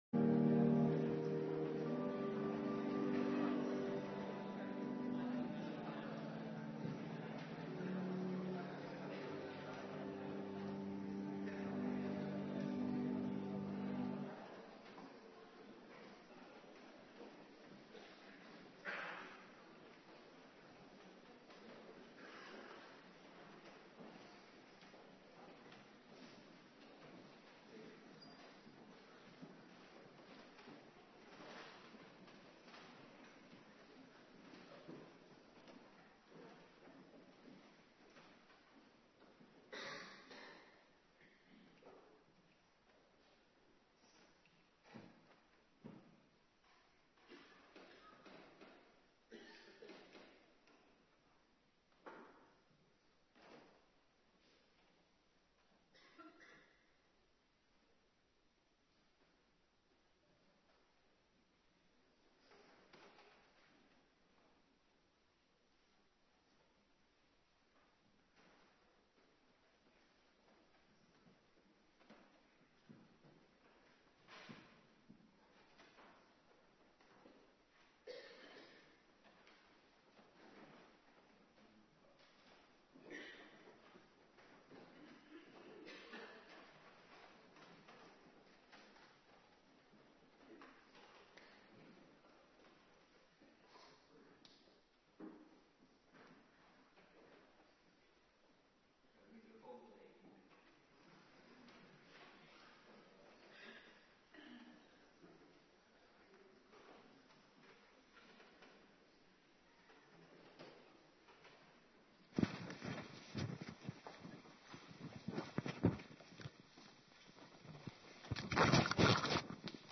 Avonddienst
18:30 t/m 20:00 Locatie: Hervormde Gemeente Waarder Agenda